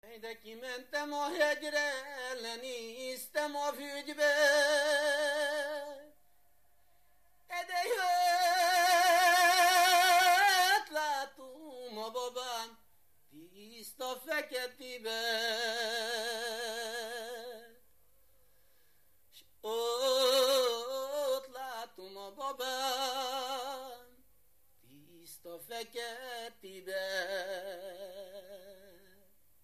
Erdély - Kolozs vm. - Türe
ének
Stílus: 3. Pszalmodizáló stílusú dallamok
Szótagszám: 12.12.12
Kadencia: 5 (b3) 1